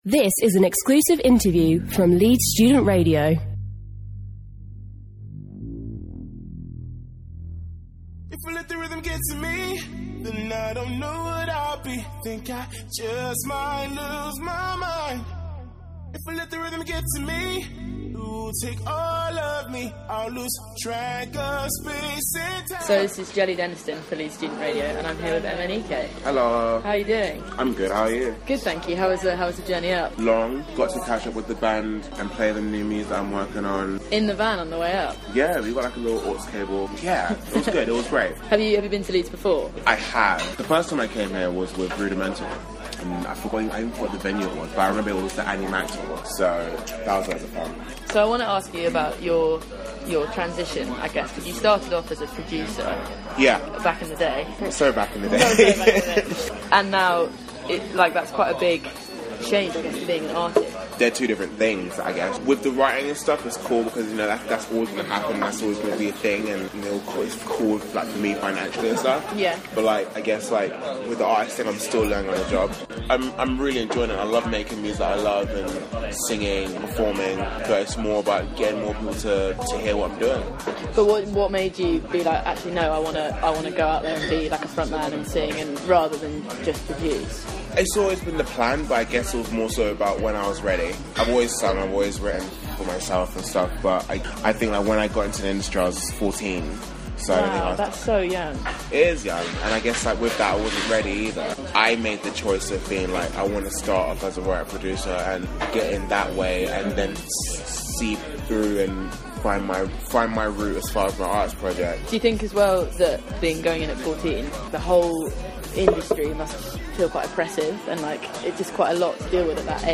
interviews
MNEK let me crash his meal to have a quick chat before his gig at Belgrave Music Hall for Live at Leeds.